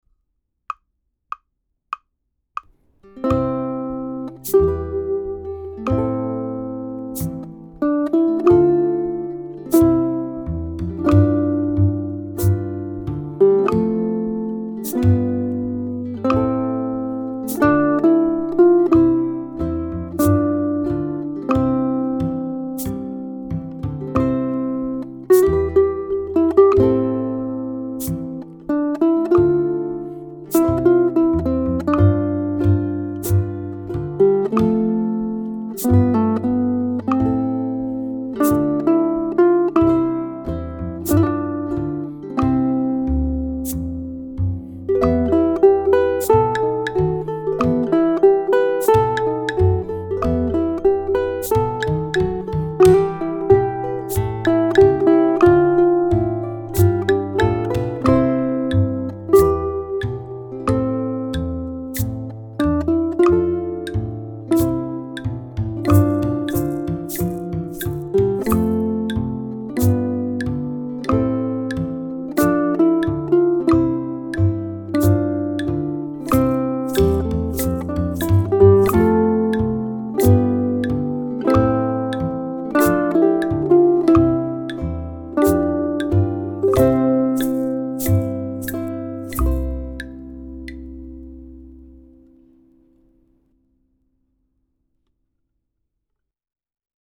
Plaisir d'amour is arranged for three guitars: guitar 1 (melody), guitar 2 (chords) and guitar 3 (bass).
Guitar 3 doesn't have any new notes or rhythms but enjoys a straightforward bass line in the first position.
I use simple thumb strums on the audio track but you're welcome to do as you please so long as you keep a beat and don't cover up the melody.
andantino